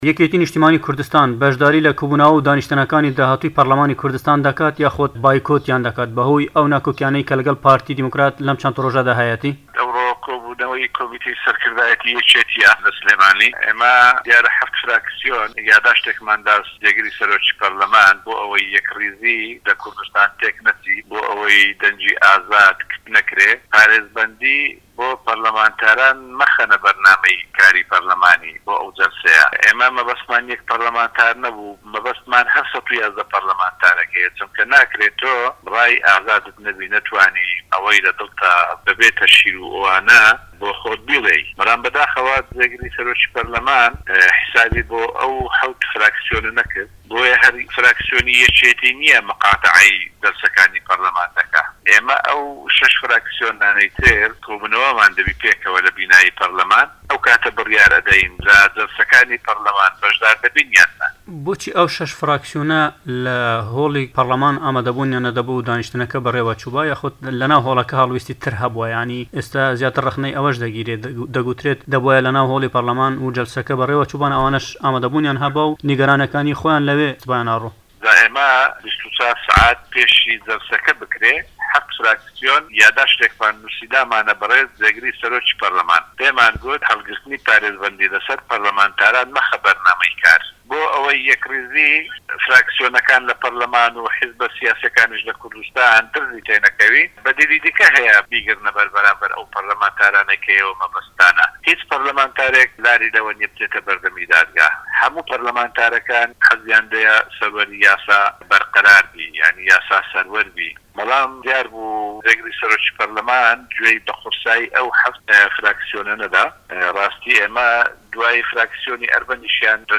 جەمال حەوێز، سەرۆكی فراكسیۆنی یەكێتی نشتیمانی كوردستان لە پەرلەمانی هەرێمی كوردستان لە وتووێژێكدا لەگەڵ دەنگی ئەمەریكا دەڵێت فراكسیۆنی ئەرمەنی لە ژێر فشار گەڕاندیانەوە بەڵام ئێستا شەش فراكسیۆن ماونەتەوە كە خاوەنی 54 كورسین لە پەرلەمان.
وتووێژ لەگەڵ جەمال حەوێز